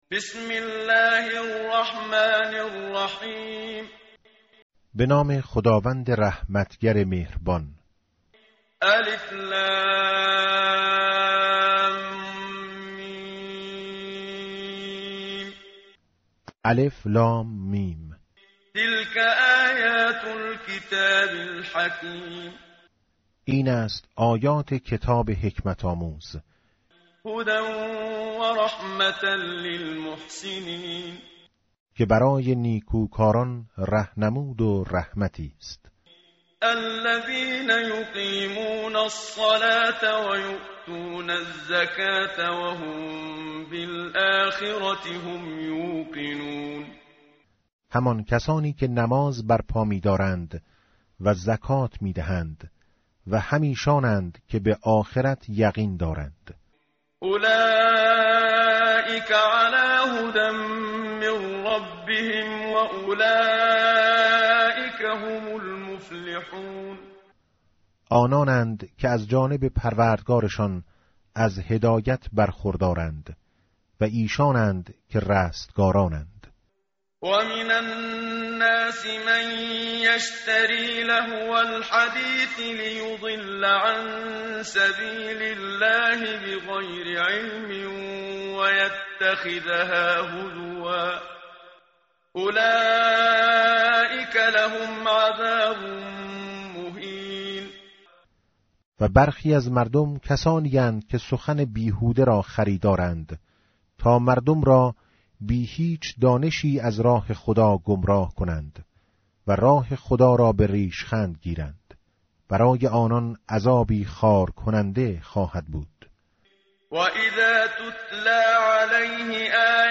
متن قرآن همراه باتلاوت قرآن و ترجمه
tartil_menshavi va tarjome_Page_411.mp3